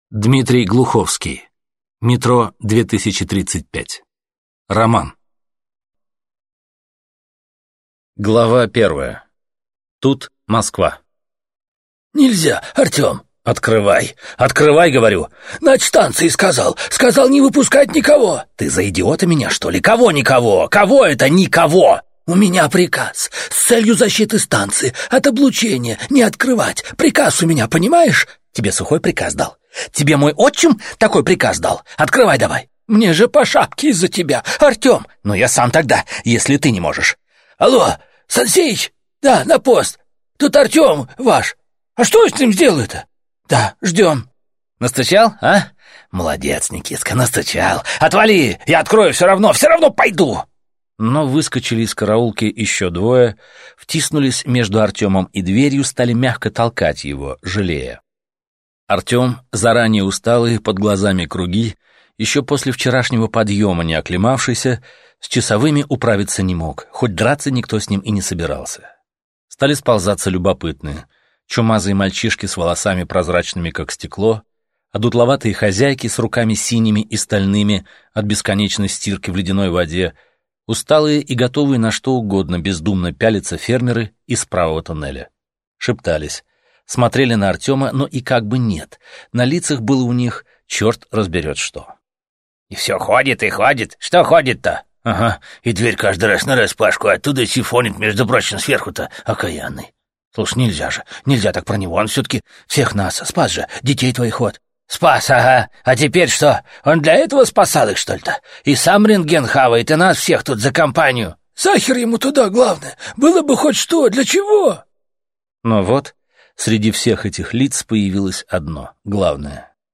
Аудиокнига Метро 2035 - купить, скачать и слушать онлайн | КнигоПоиск